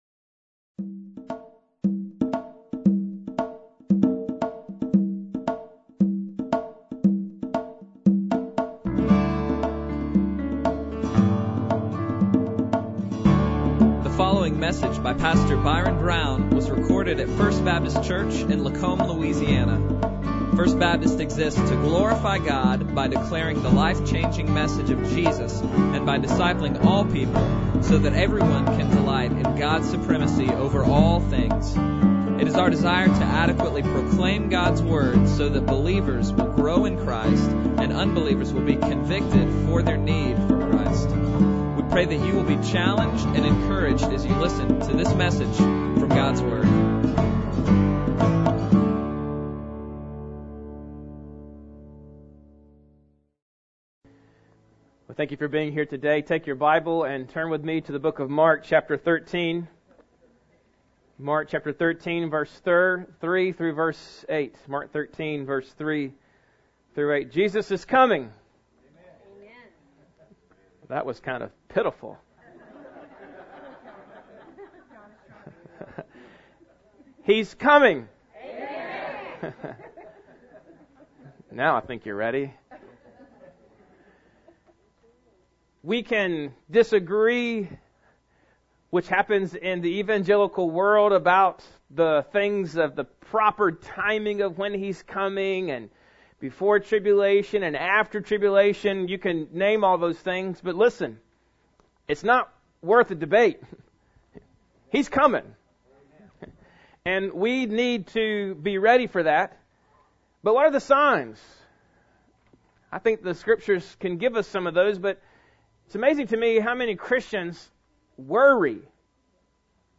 Bible Text: Mark 13:3-8 | Preacher